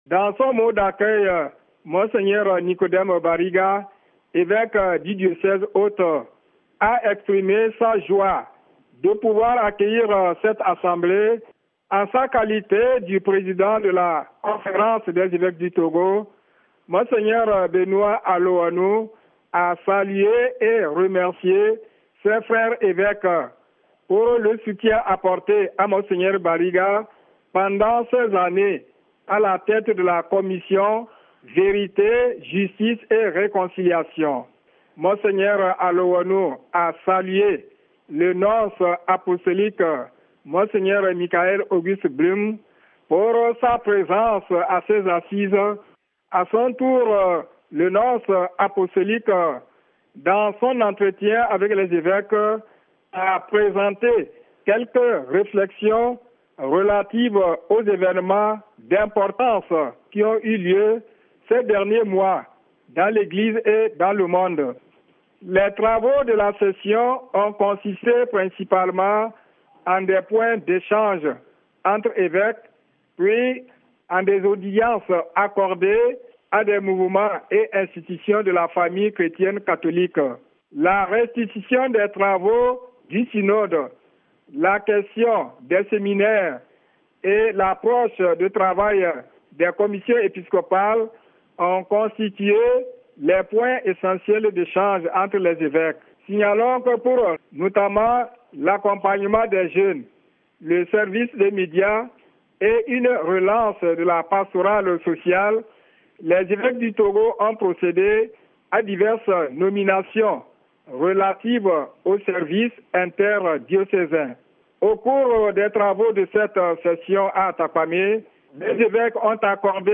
en voici des détails avc notre correspondant local